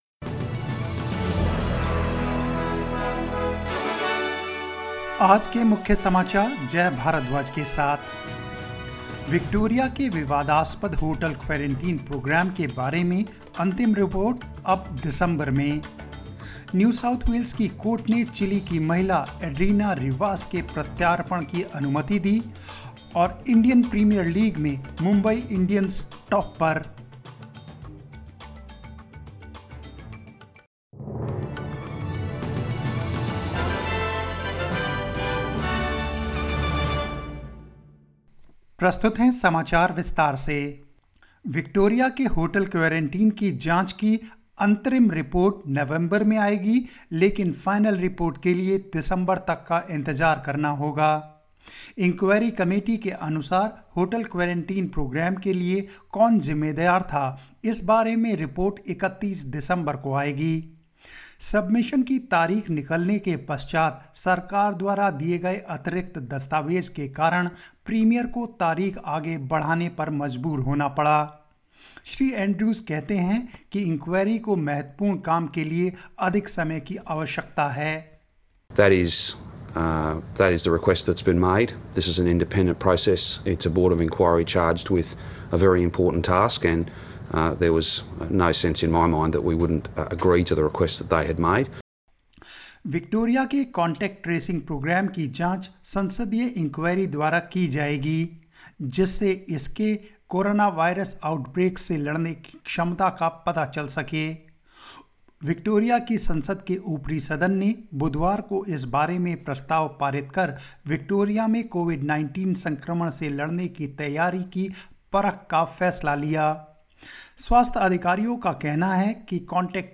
News in Hindi 29 October 2020